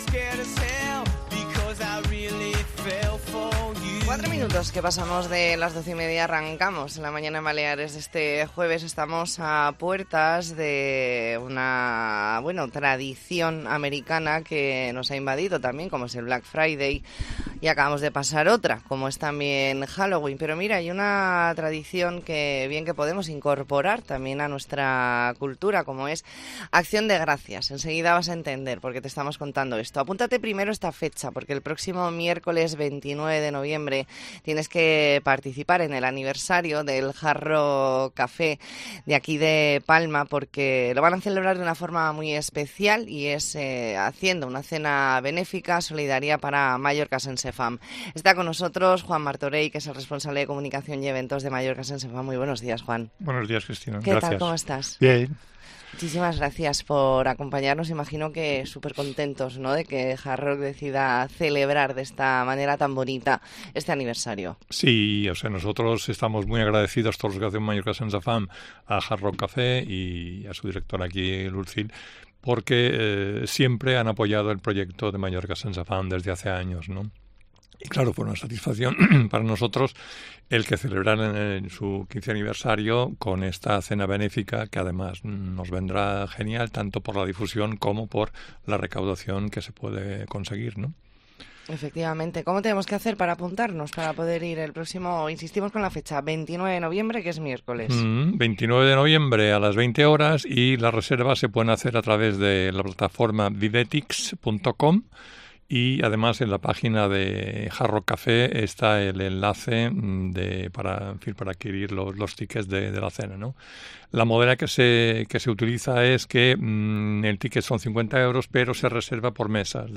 Entrevista en La Mañana en COPE Más Mallorca, jueves 16 de noviembre de 2023.